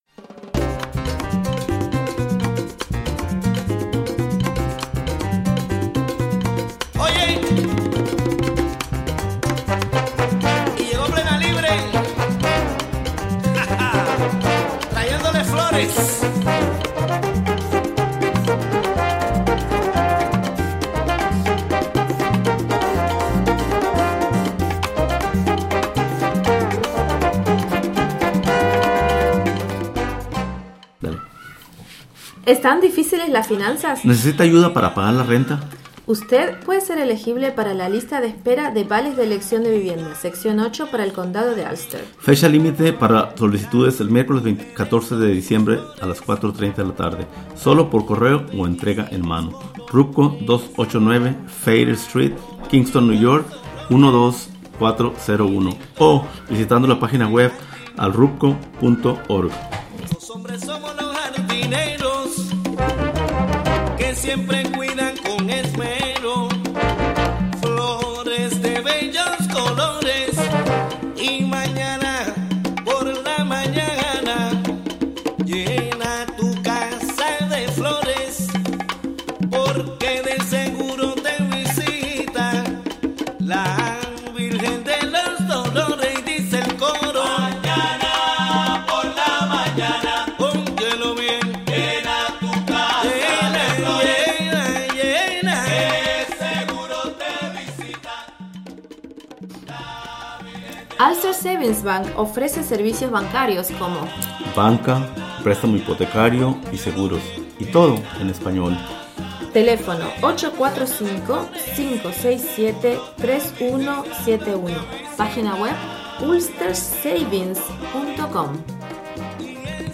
9am Un programa imperdible con noticias, entrevistas,...